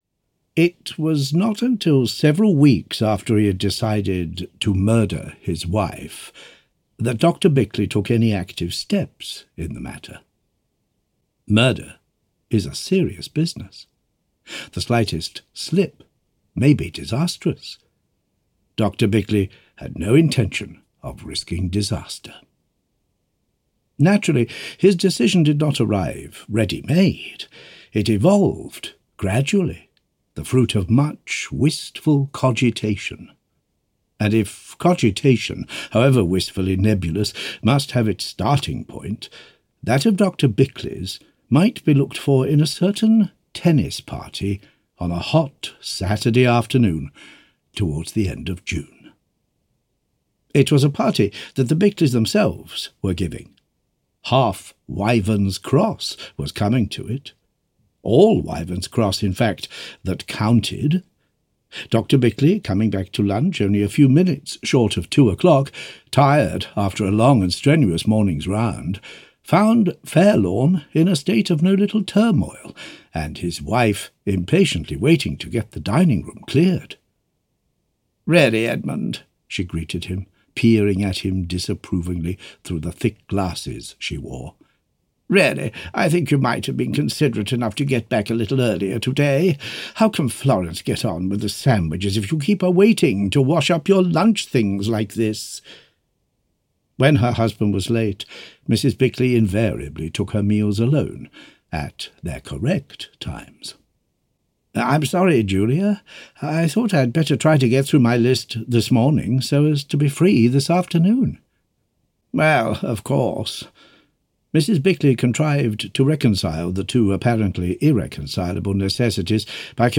Malice Aforethought audiokniha
Ukázka z knihy